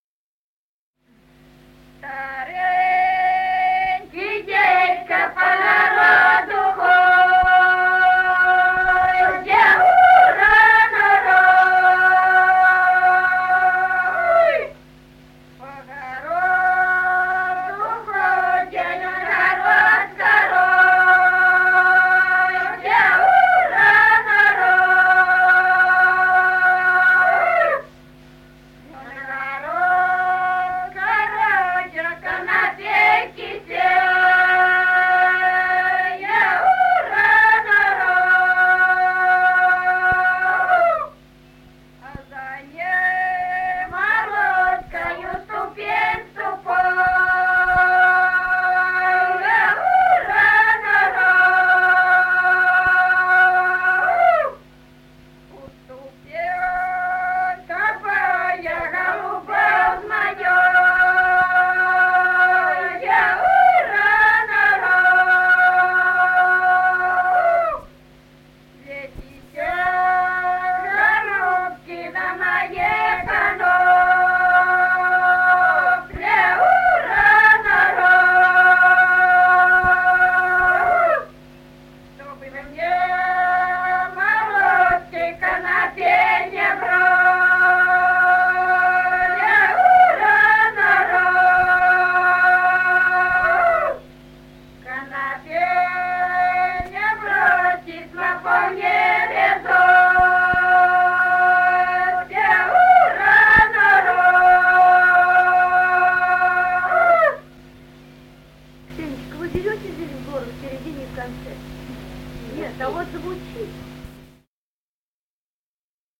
Народные песни Стародубского района «Старенький дедька», гряная.
с. Курковичи.